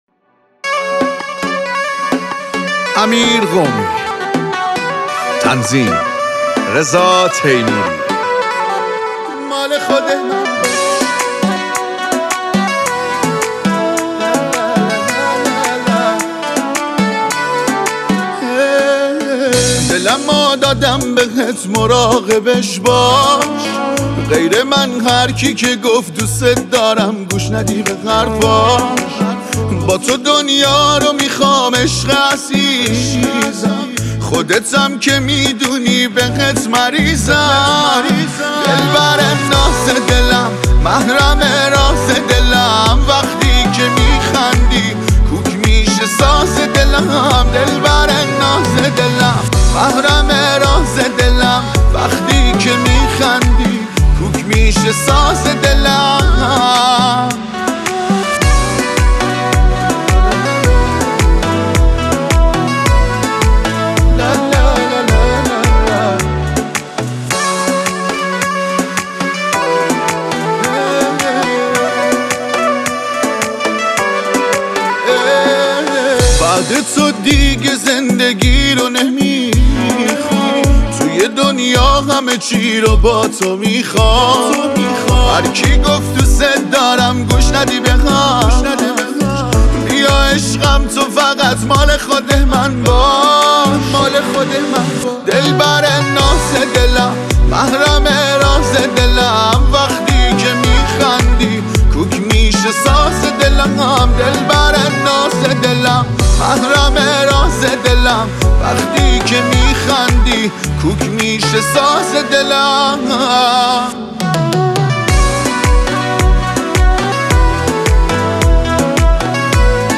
خواننده مازندرانی